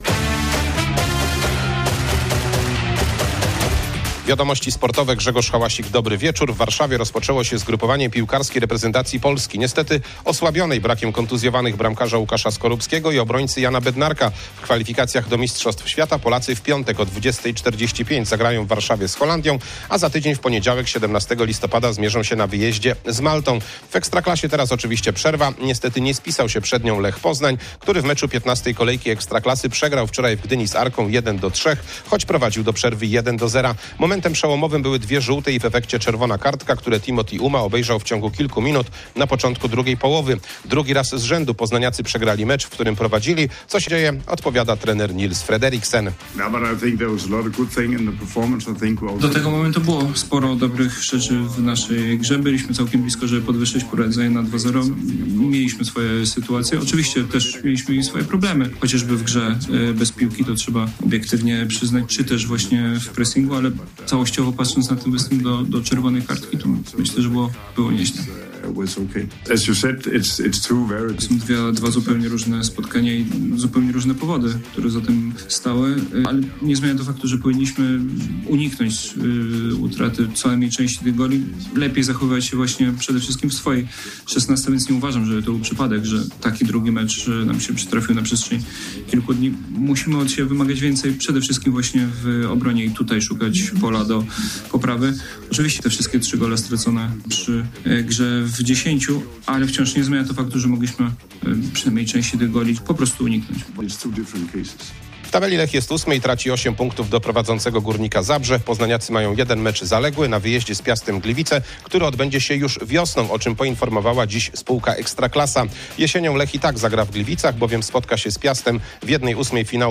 10.11.2025 SERWIS SPORTOWY GODZ. 19:05